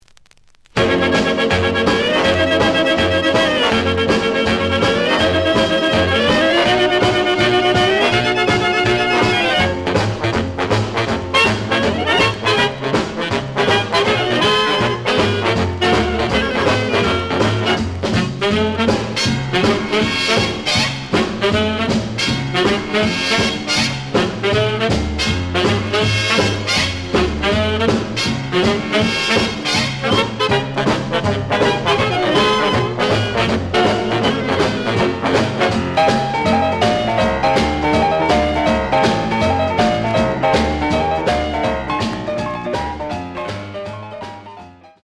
The audio sample is recorded from the actual item.
●Genre: Big Band